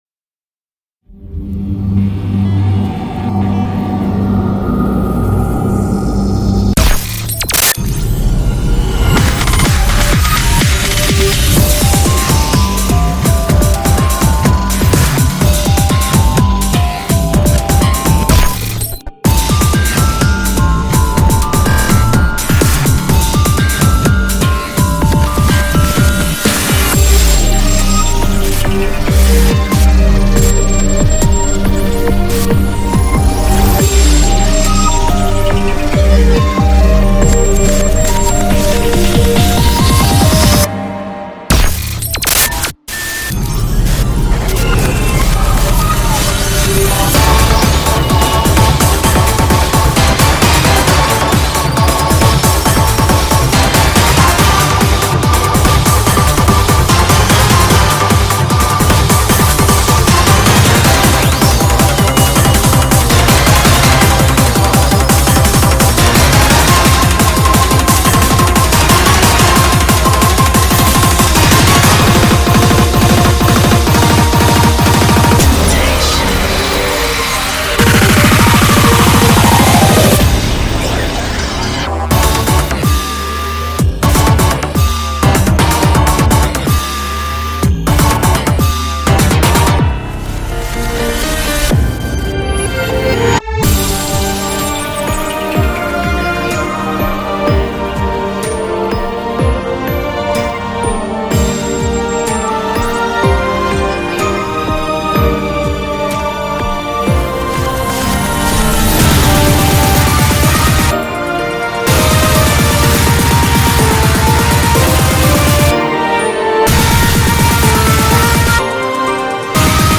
BPM35-400
Audio QualityPerfect (High Quality)